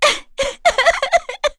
Erze-Vox_Sad.wav